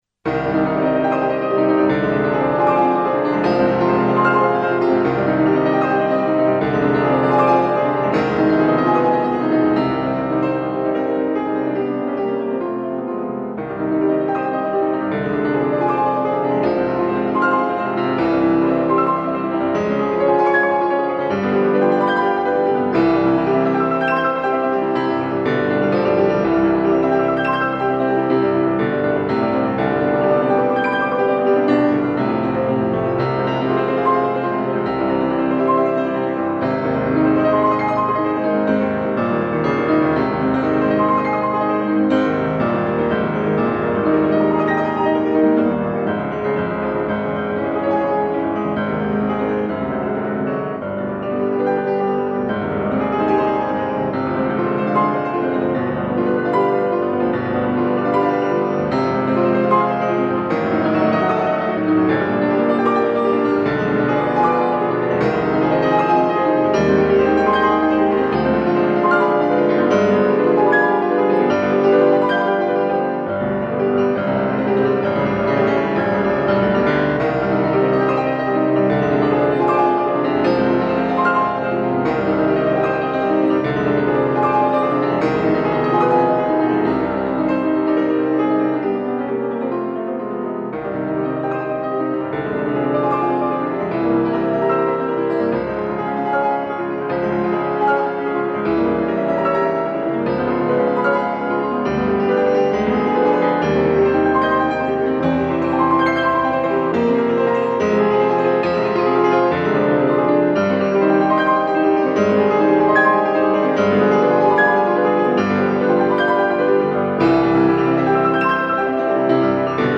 Frederic Chopin. Etudes op.25 №12 in С minor "Ocean" ...